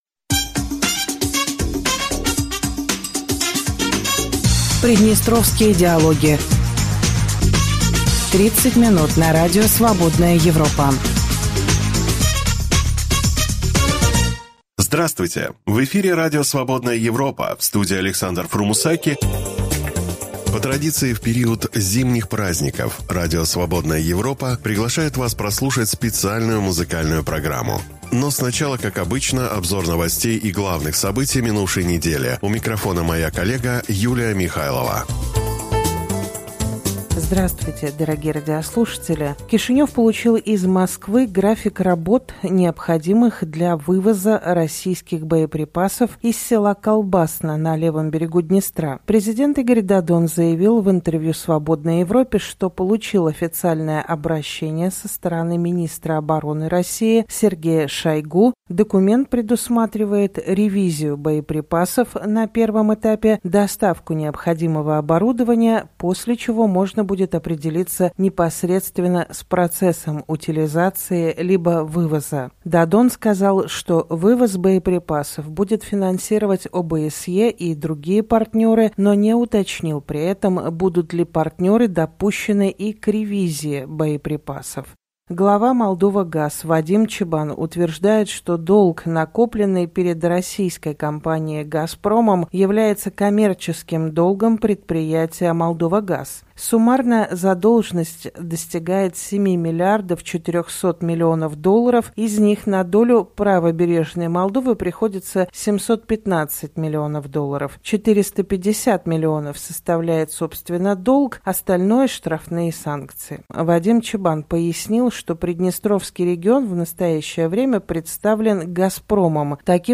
По традиции, в период зимних праздников Радио Свободная Европа приглашает вас послушать специальную музыкальную программу. Но сначала, как обычно, обзор новостей и главных событий...